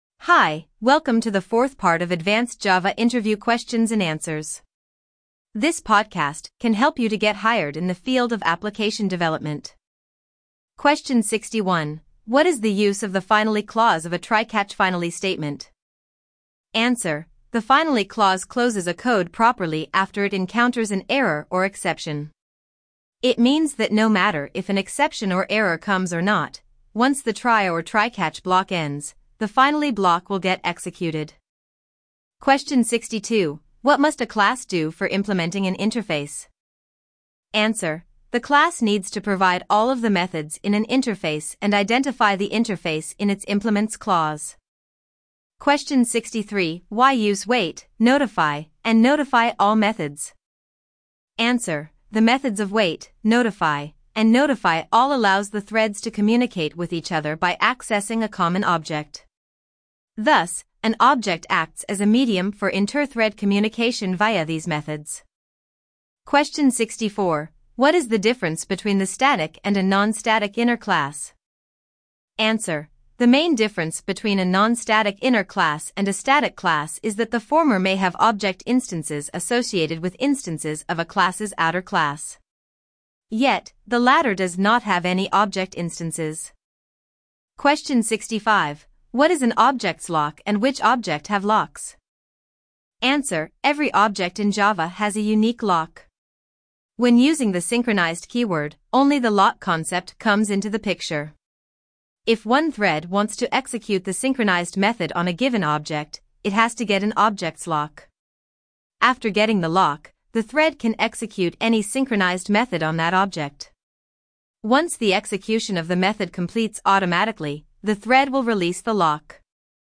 LISTEN TO THE ADVANCED JAVA FAQs LIKE AN AUDIOBOOK